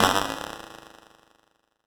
Session 11 - Bit Hit.wav